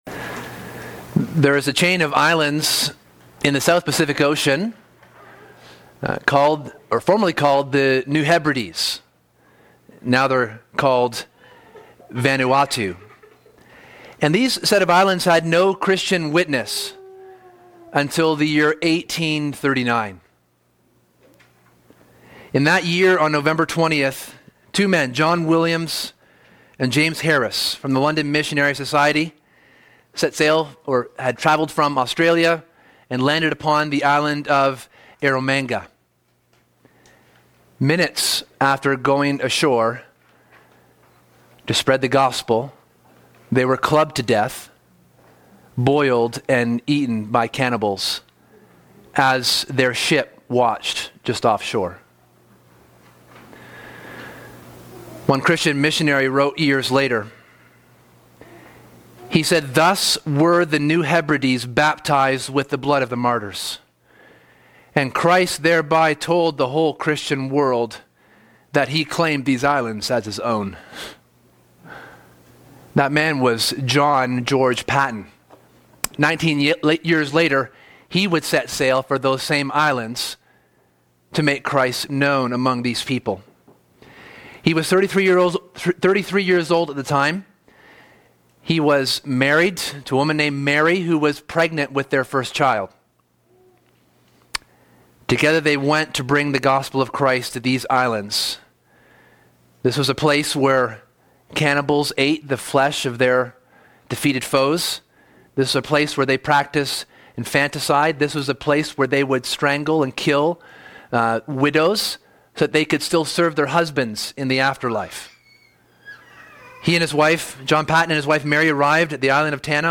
This book, and thus this sermon series, explores the nature and character of God.